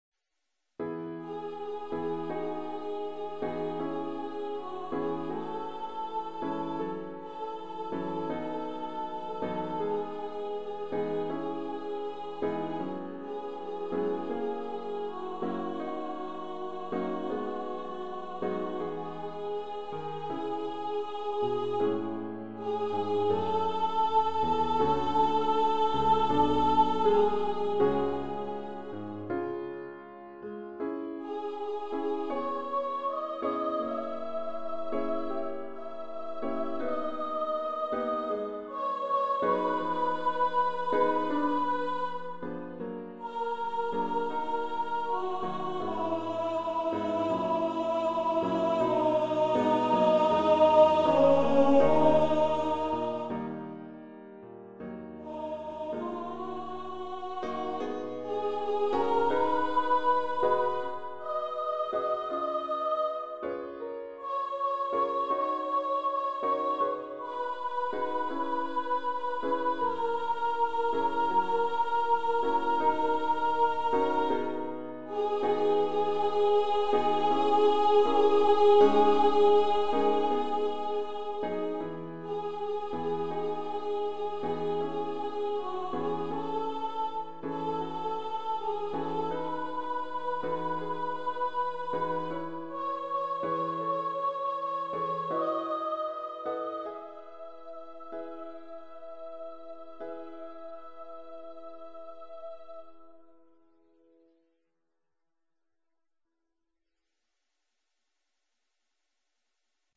for Voice and Piano
Composer's Demo